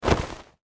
religion / Assets / 音效 / 跳跃.mp3
跳跃.mp3